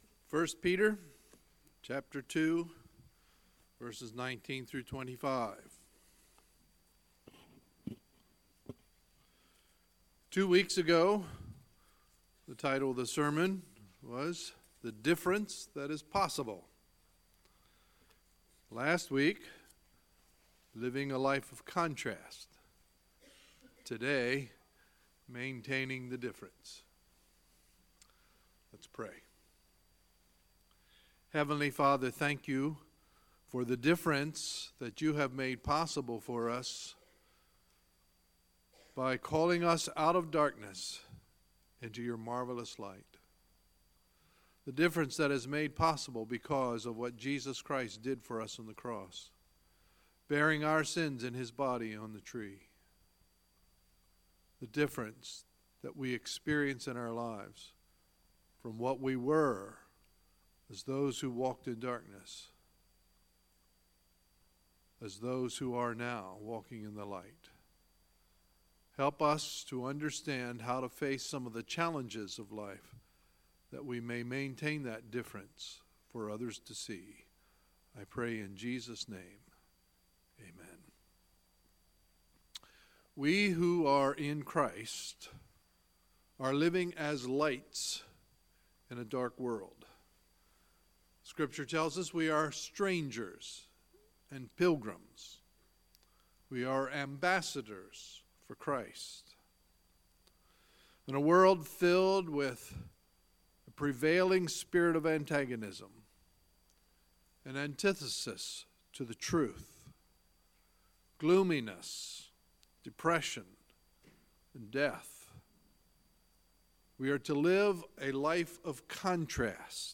Sunday, May 6, 2018 – Sunday Morning Service
Sermons